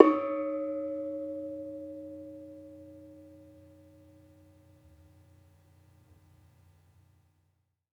Bonang-D#3-f.wav